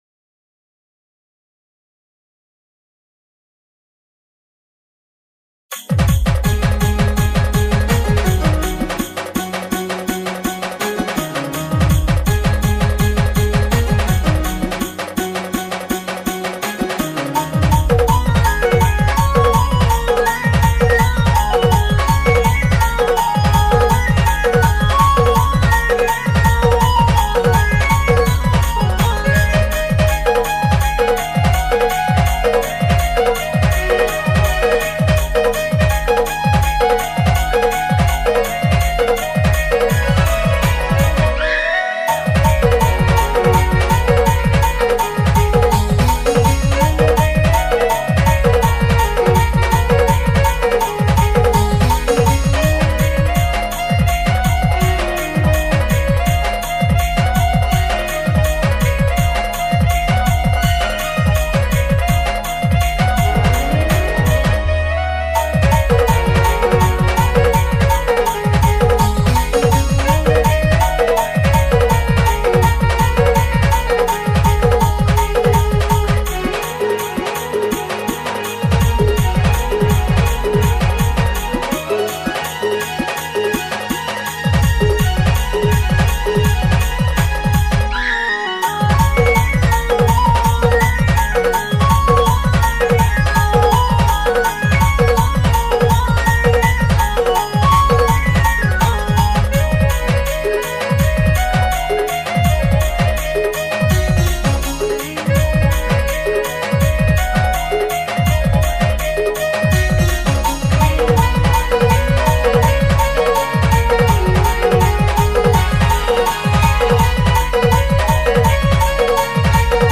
Instrumental Music
SAMBALPURI INSTRUMENT DJ REMIX